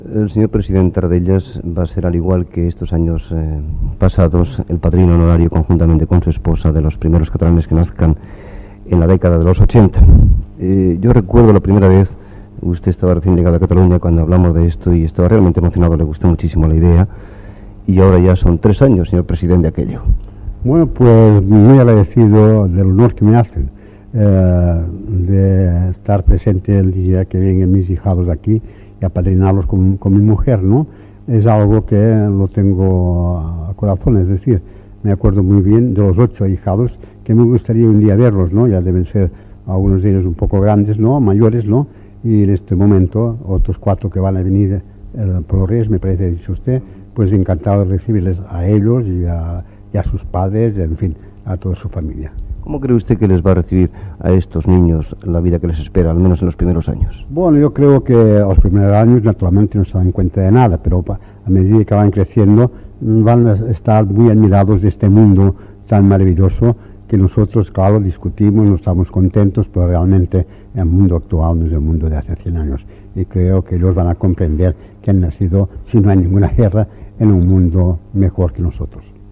Entrevista al president de la Generalitat de Catalunya, Josep Tarradellas, com a padrí dels primers catalans de l'any 1980.